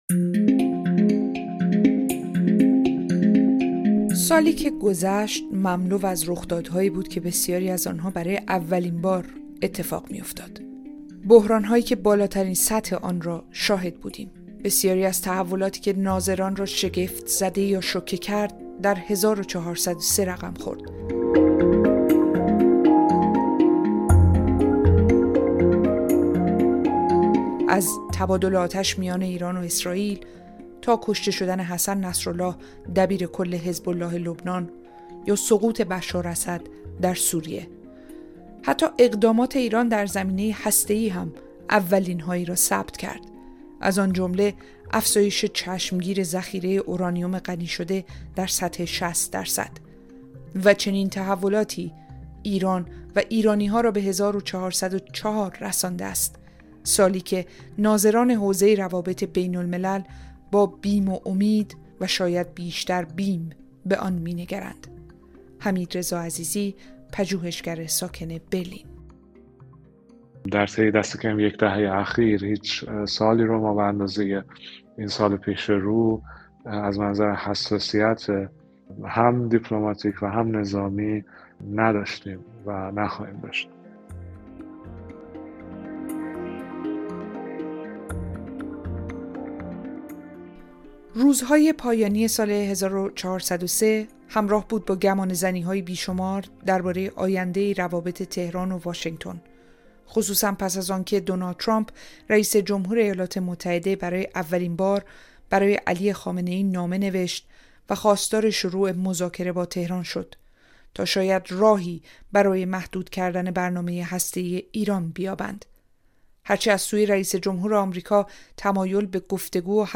در گفت‌وگو با کارشناسان حوزه سیاست خارجی به بررسی شرایط موجود و چشم‌انداز وضعیت ایران در سطح بین‌الملل پرداخته‌ایم. آیا ایران به سمت مذاکره حرکت می‌کند یا تقابل؟